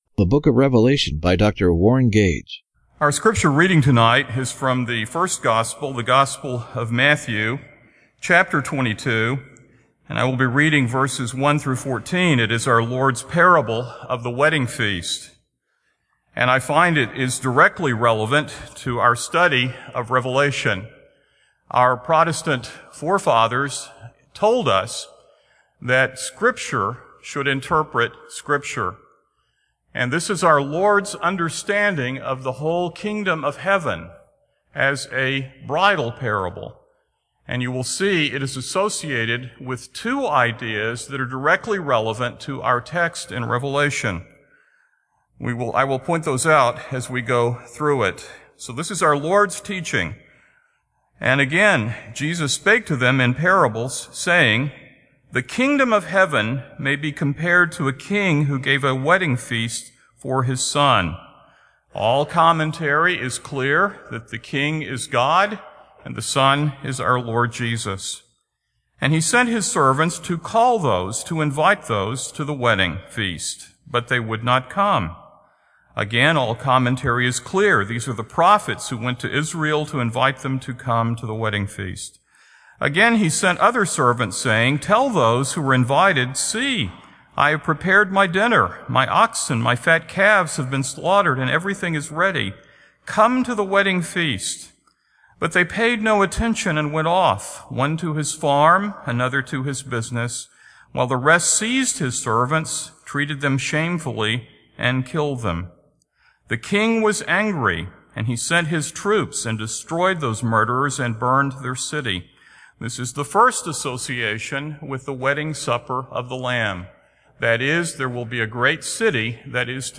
Audio Sermon Series